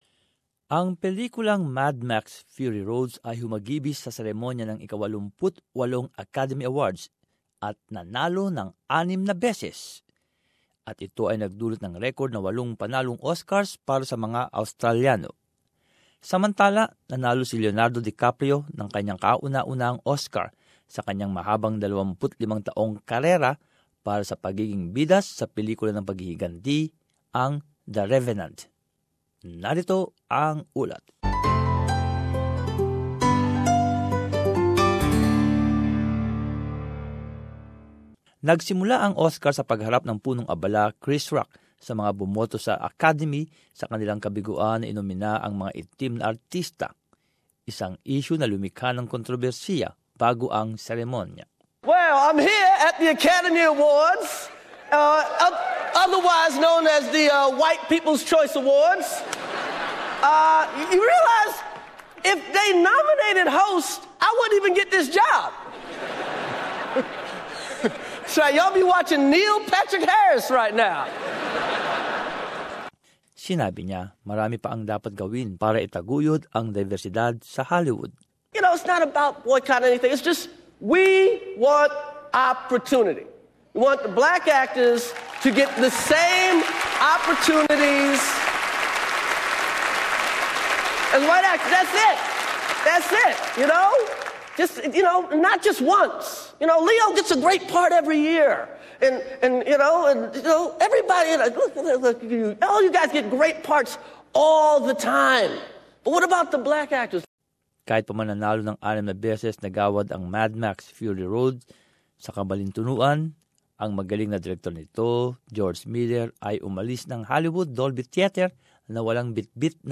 Here's a portion of Channel 9 coverage